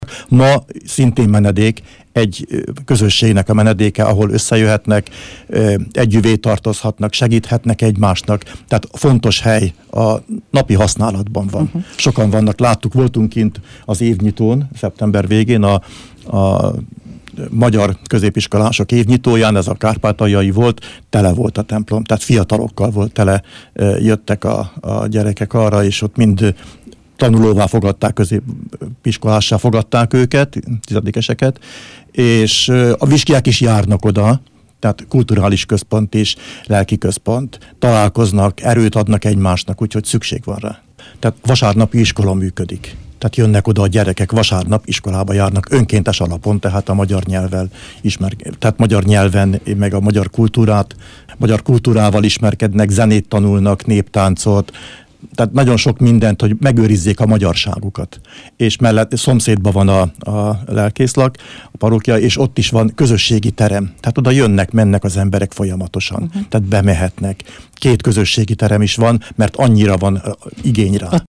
Mezősi Árpád önkormányzati képviselő és nemzetközi kapcsolatokért felelős tanácsnok rádiónknak arról beszélt, hogy a templom menedék, kulturális és szellemi tér is az ott élő magyarság számára.